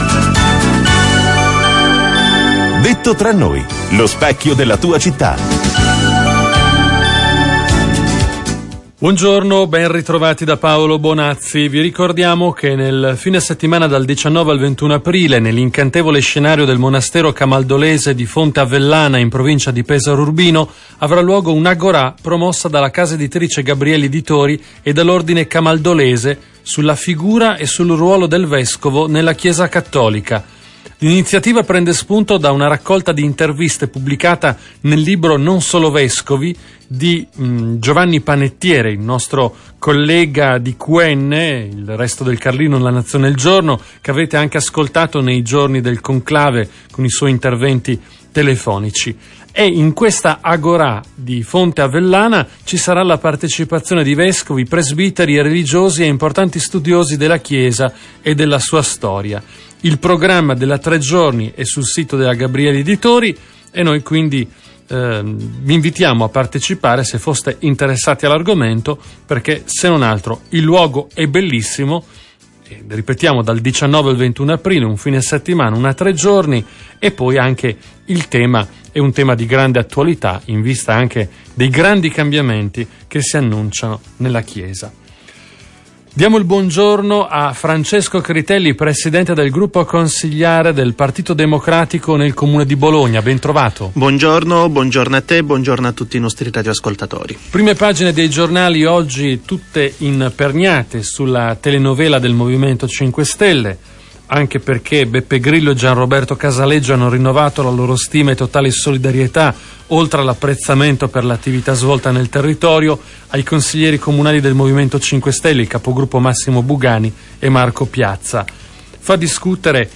In allegato, il file audio dell'intervista radio.